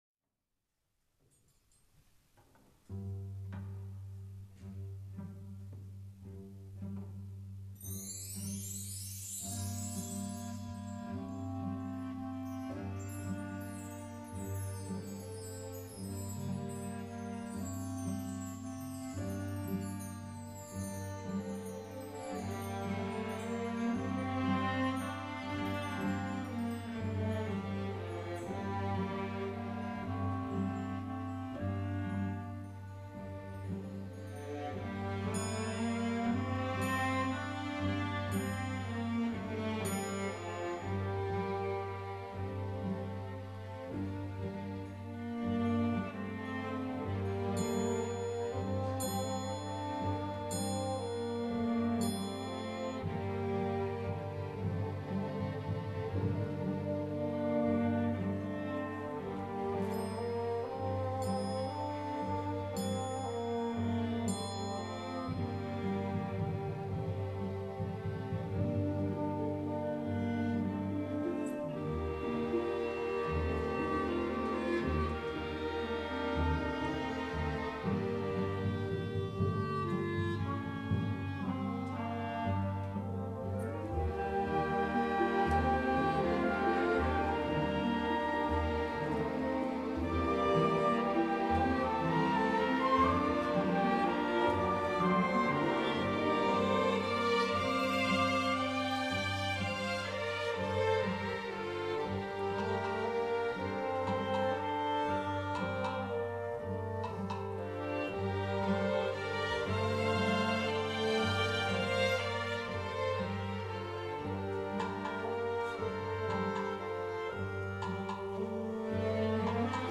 Dies ist der langsame Satz der 3ss�tzigen Achill Island Suite.